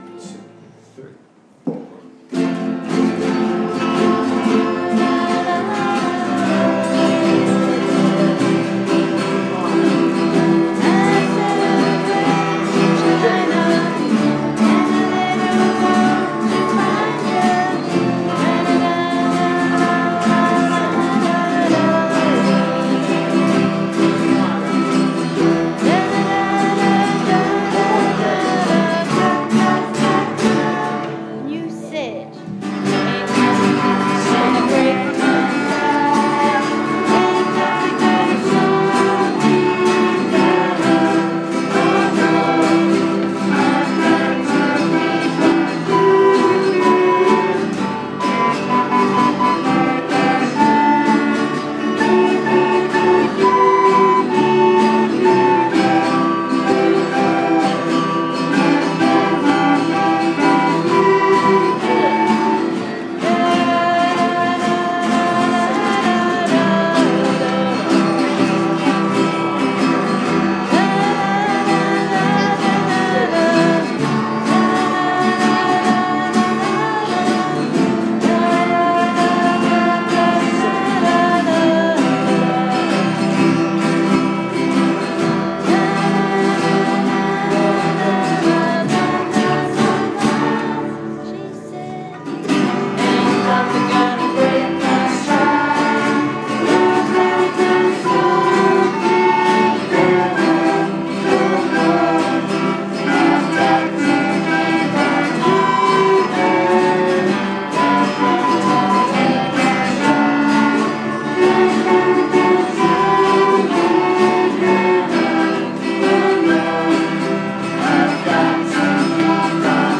Band practice